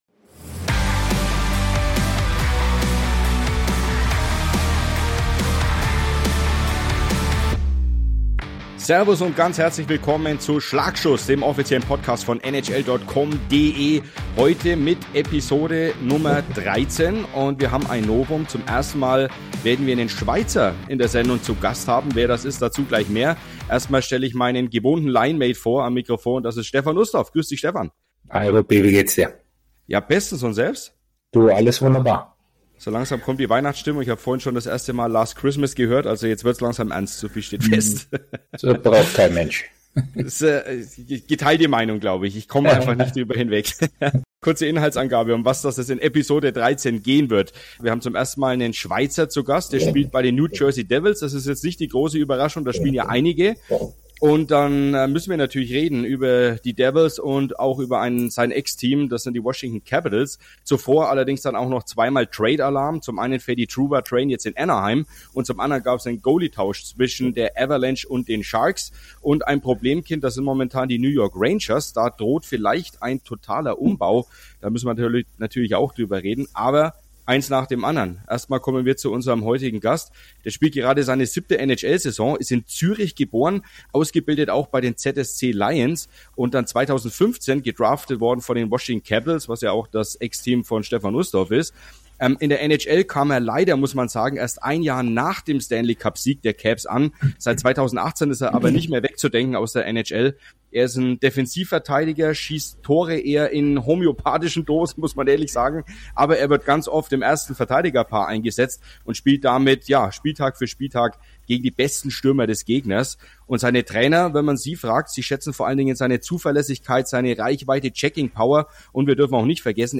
Mit Jonas Siegenthaler (New Jersey Devils) ist erstmals ein Schweizer NHL-Profi zu Gast im Podcast. Themen sind außerdem die Trades von Jacob Trouba und Mackenzie Blackwood, die heikle Situation bei den New York Rangers sowie die Überraschungsmannschaft Washington Capitals